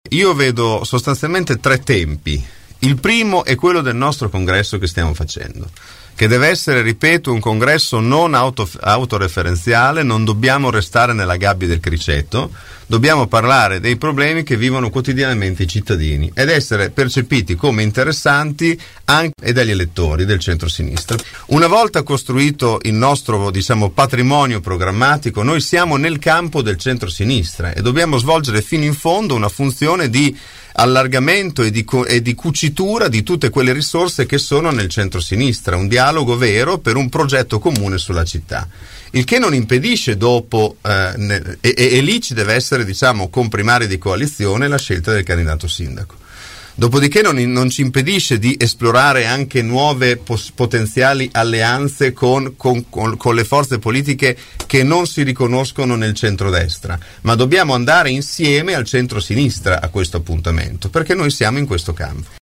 ospiti dei nostri studi.